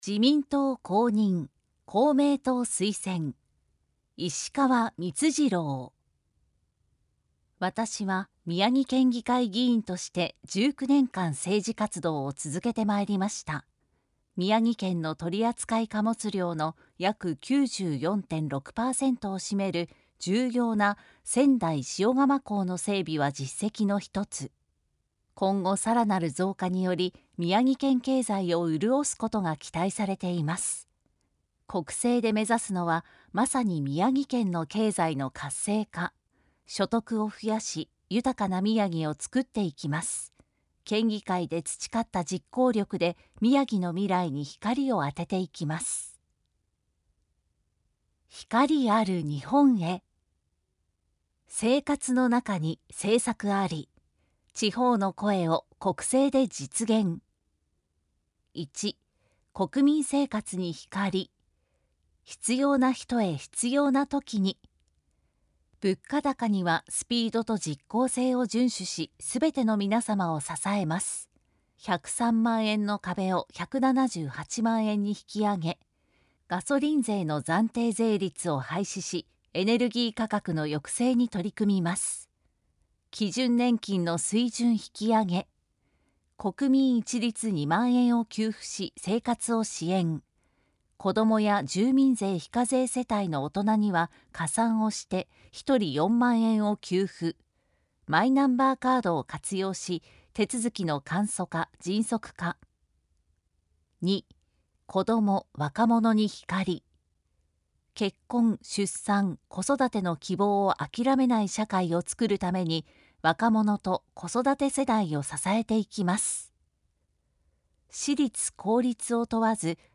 参議院議員通常選挙候補者・名簿届出政党等情報（選挙公報）（音声読み上げ用）（音声版）
選挙公報音声版（MP3：4,515KB）